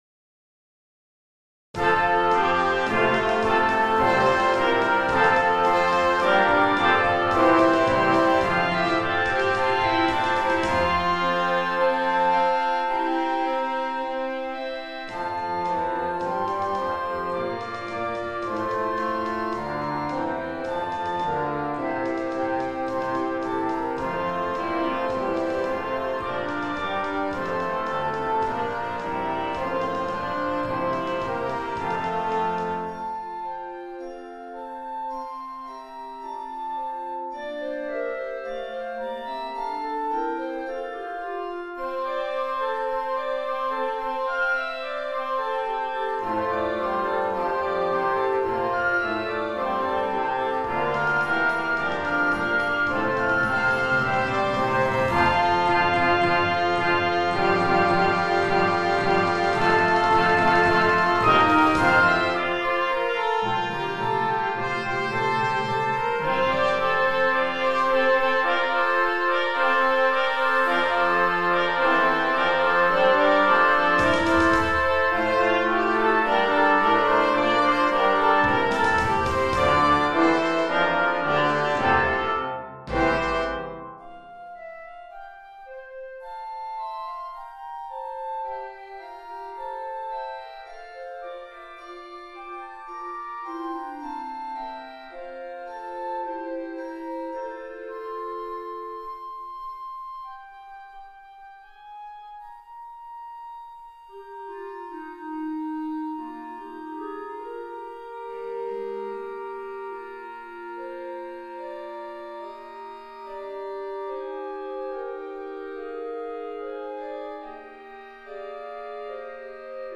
Concert Band Grade 3